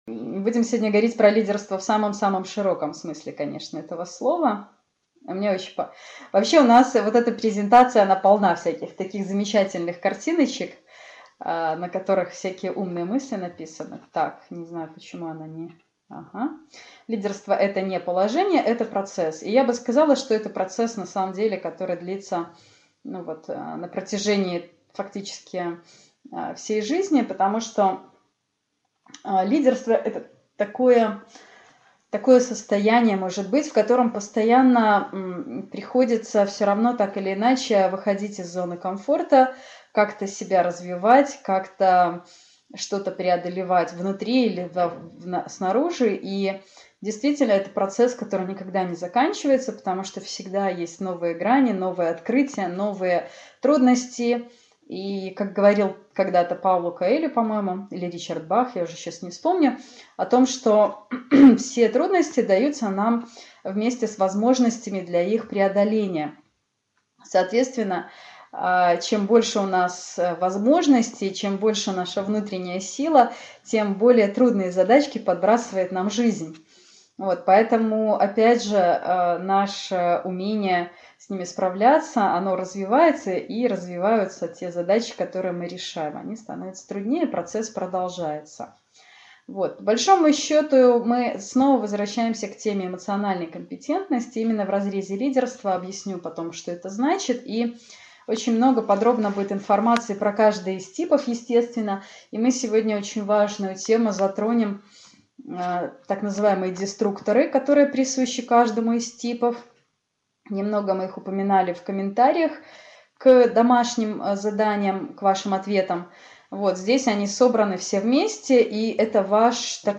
Аудиокнига Стили лидерства | Библиотека аудиокниг